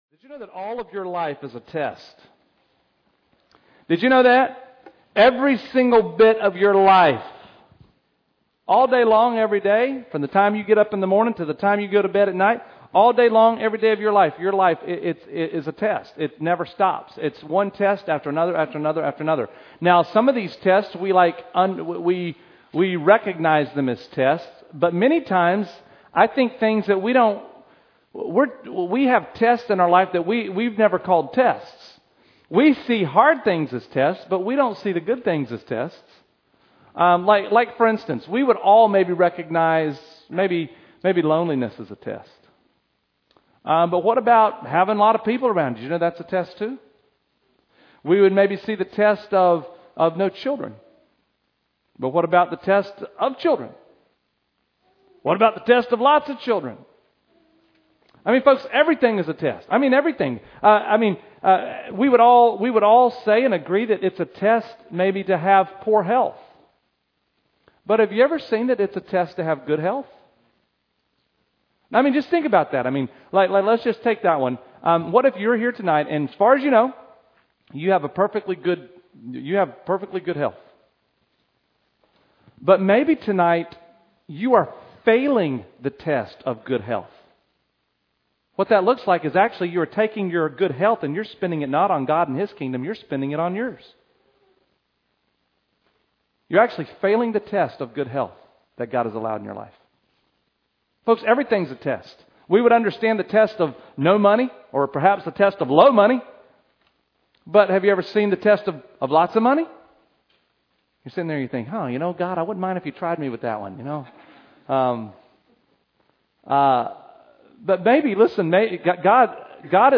Service Type: Revival Service